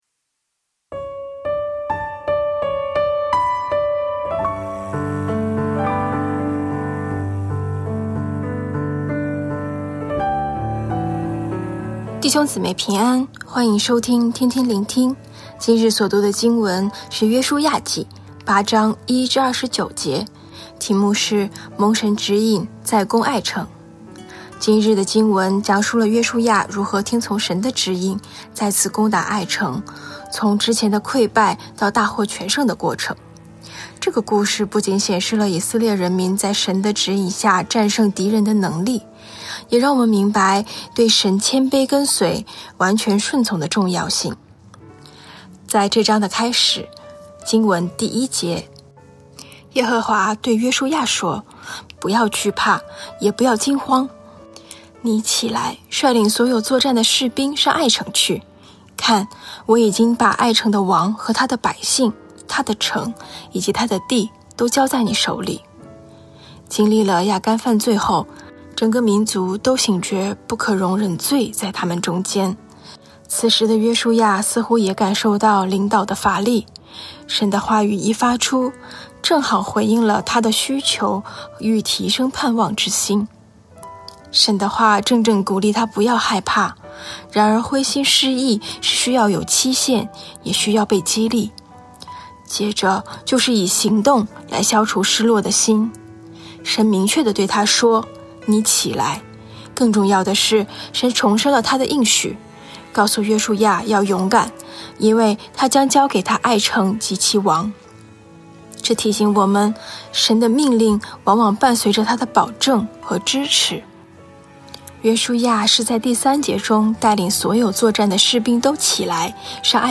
普通话录音连结🔈